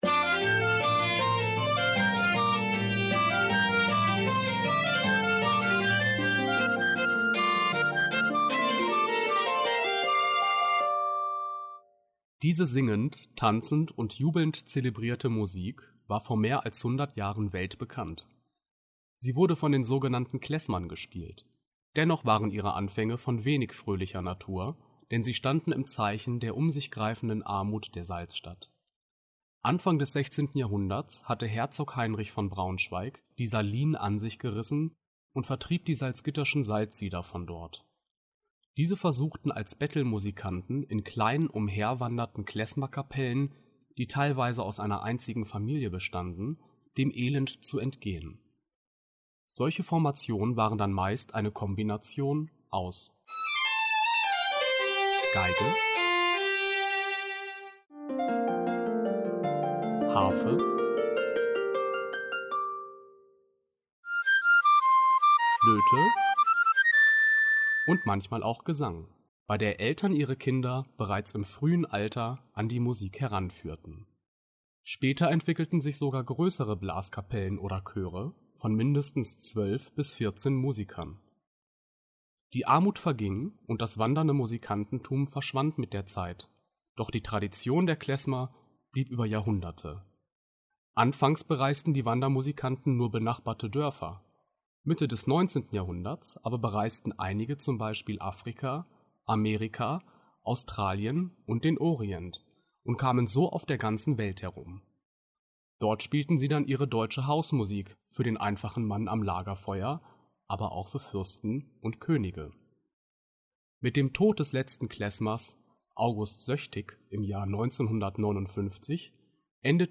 audioguide-zur-skulptur-die-klesmer-in-salzgitter-bad.mp3